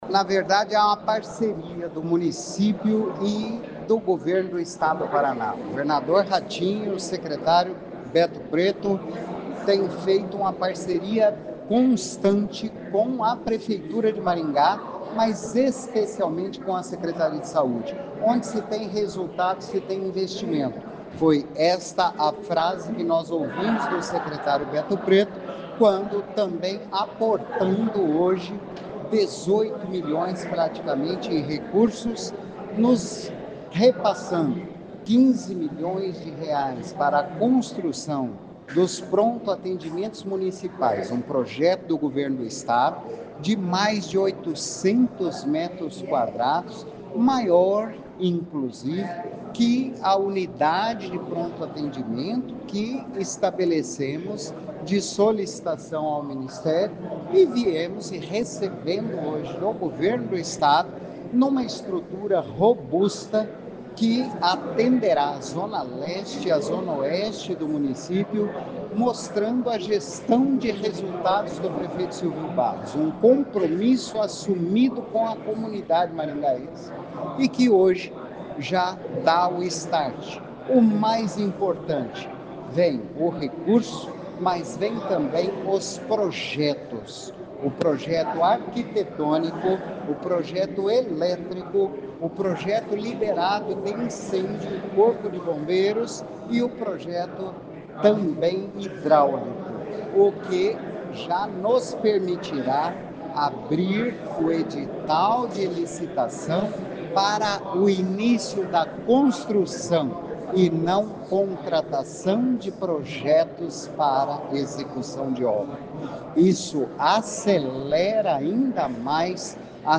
Ouça o que diz o secretário de Saúde de Maringá, Antônio Carlos  Nardi: